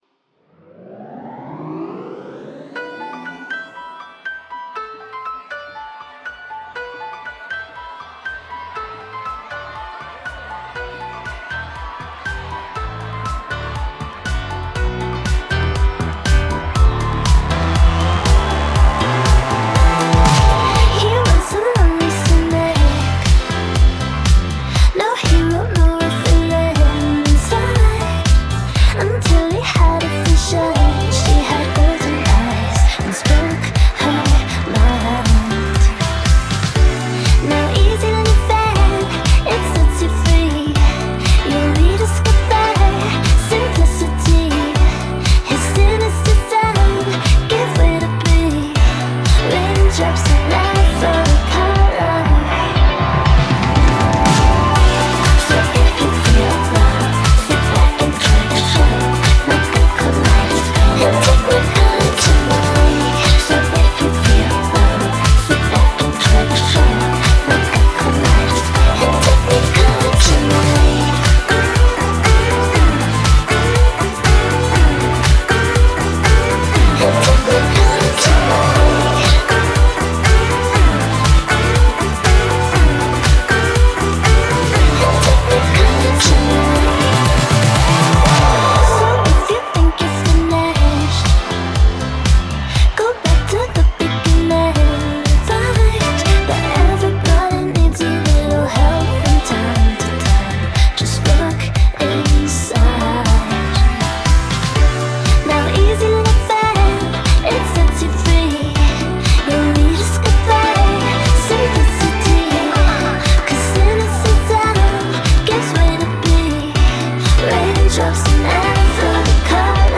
BPM120-120
Alternative Pop song for StepMania, ITGmania, Project Outfox